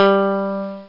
Bass Guitar Long Sound Effect
Download a high-quality bass guitar long sound effect.
bass-guitar-long.mp3